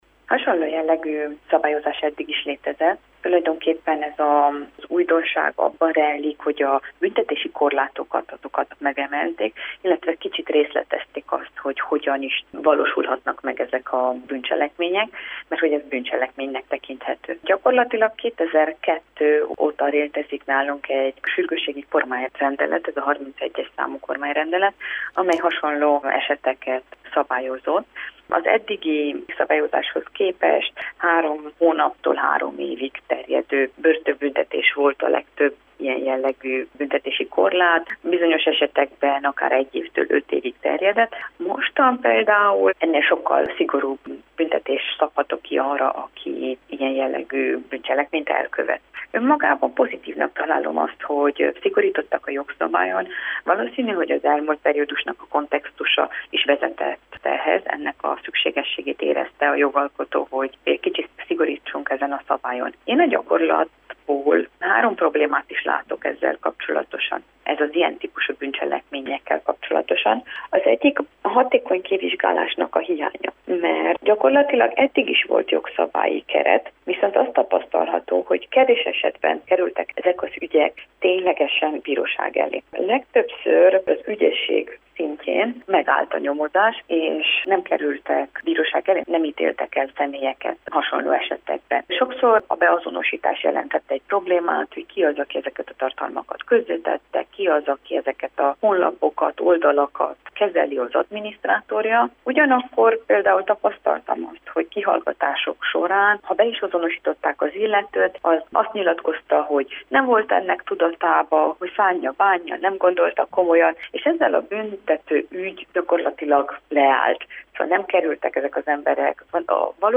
Jogásszal beszélgettünk a törvényszigorításról, amely által börtönnel büntetik, ha valaki antiszemita, fasiszta, legionárius, xenofób vagy holokauszttagadó tartalmakat oszt meg az interneten.